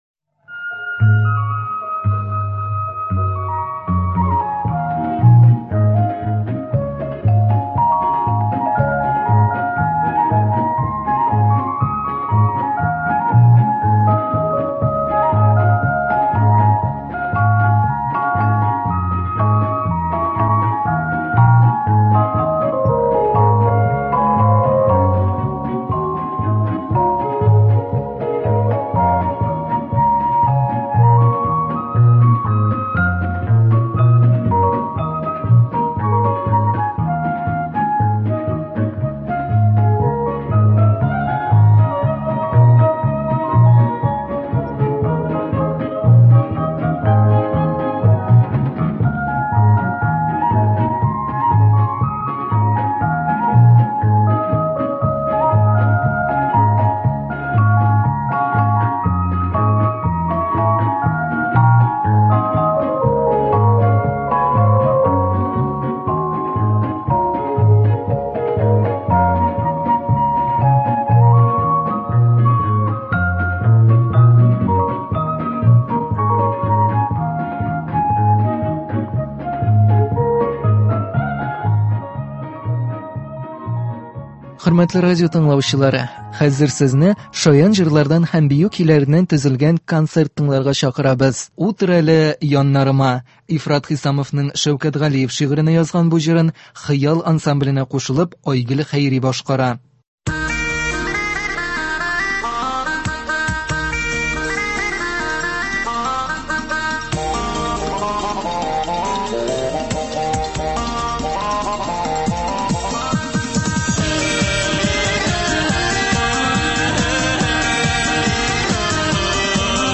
Шаян җырлар һәм биюләр.